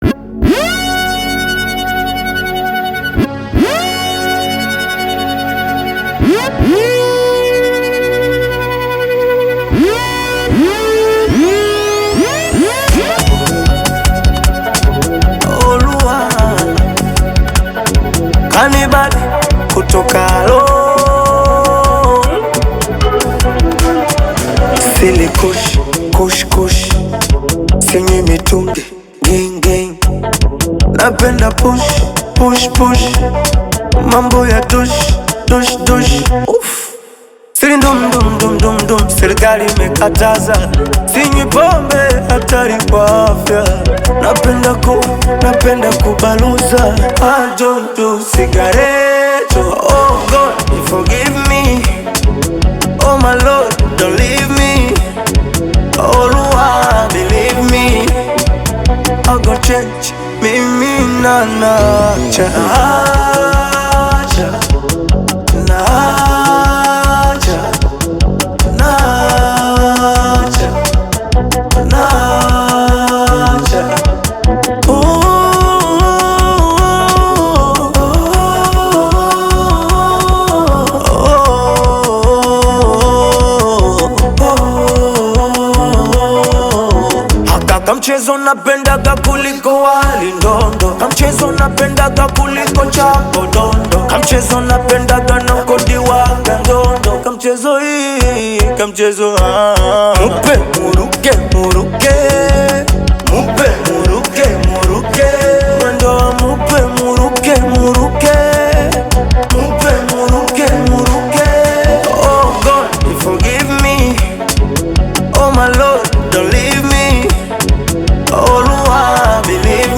inspirational Afro-pop single